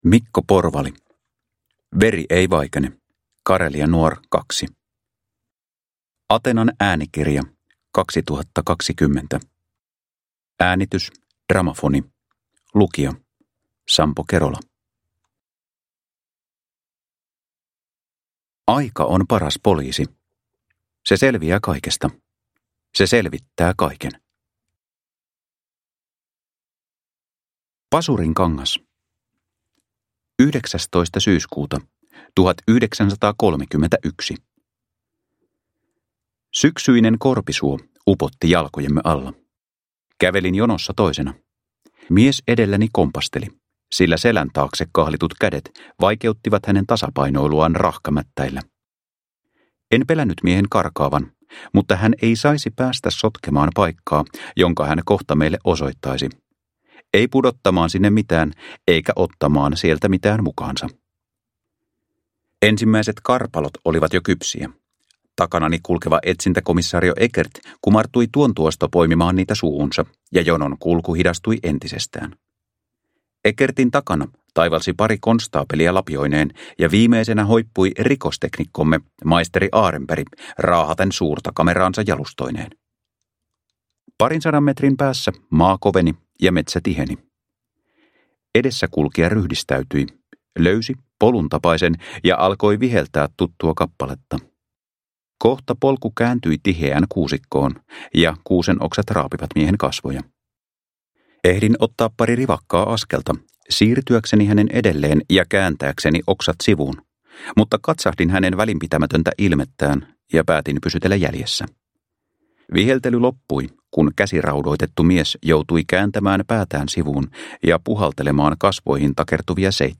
Veri ei vaikene – Ljudbok – Laddas ner